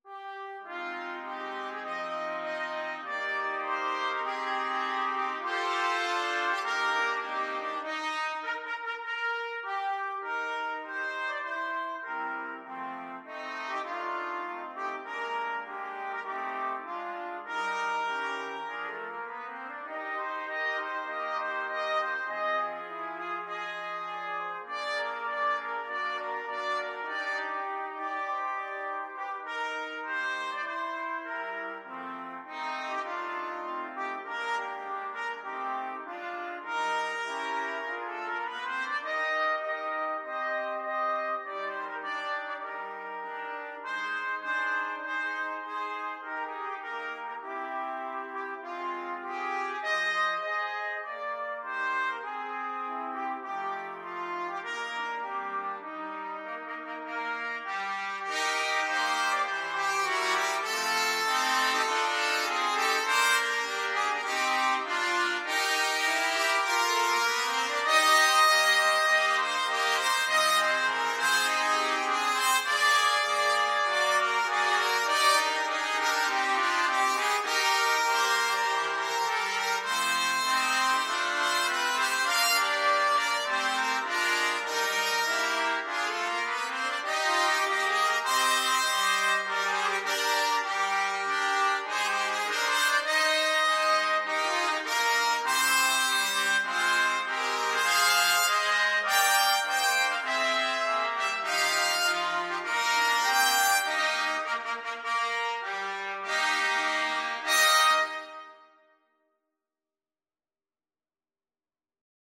Moderato = c. 100
4/4 (View more 4/4 Music)
Jazz (View more Jazz Trumpet Quartet Music)
Rock and pop (View more Rock and pop Trumpet Quartet Music)